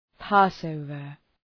Προφορά
{‘pɒs,əʋvər}